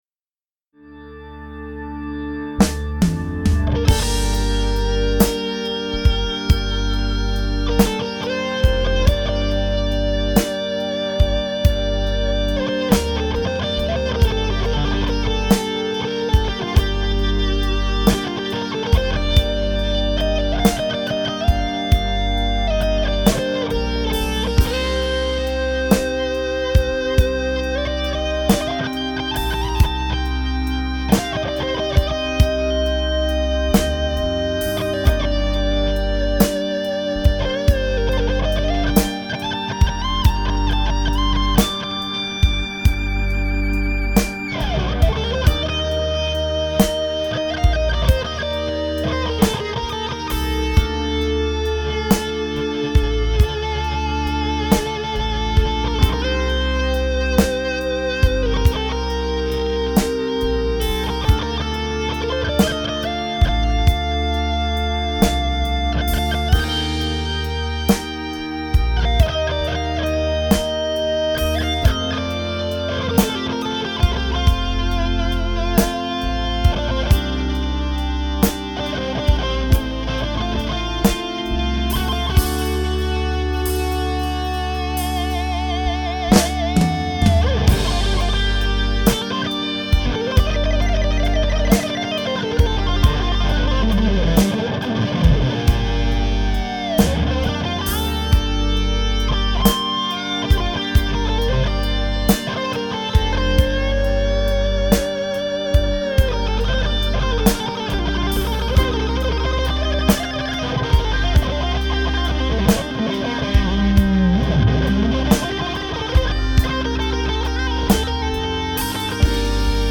High gain Solo Floydian Hi gain solo. Channel 4
Fat lead impro.mp3